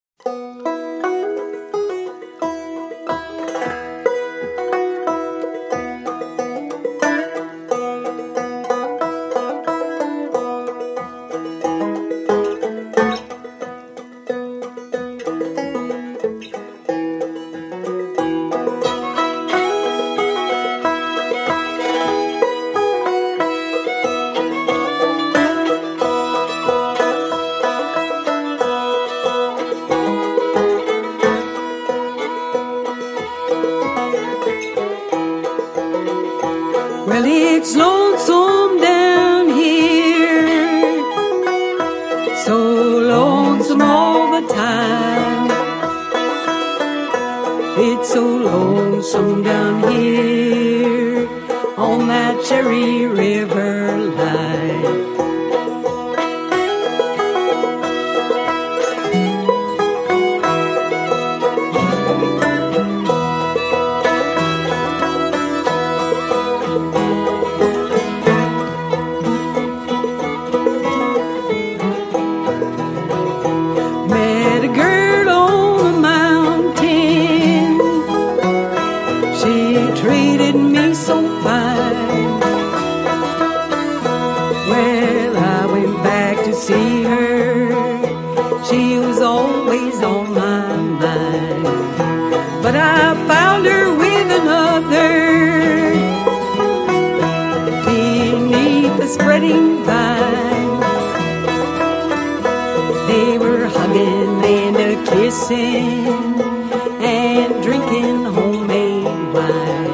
This is a West Virginia tune.